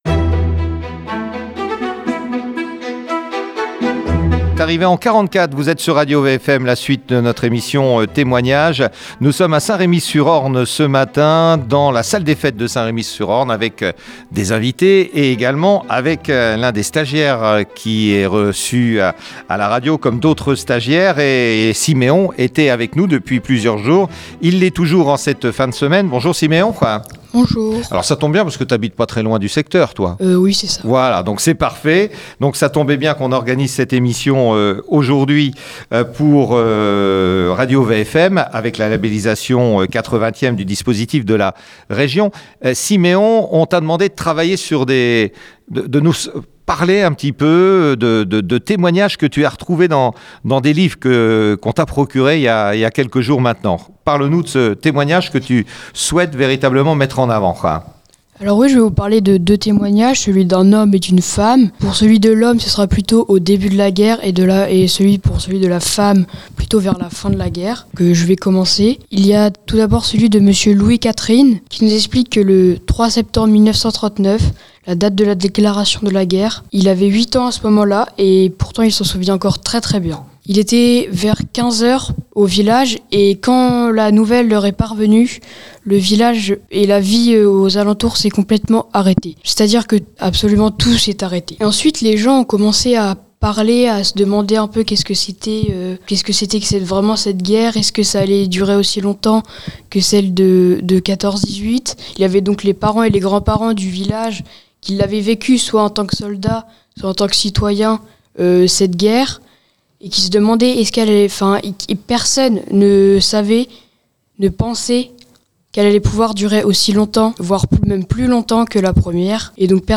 Émission exceptionnelle diffusée Dimanche 16 Juin à Saint-Remy sur Orne à 10 h 15. Témoignages, récits de la libération du Bocage.